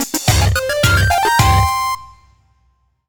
retro__musical_stinger_03.wav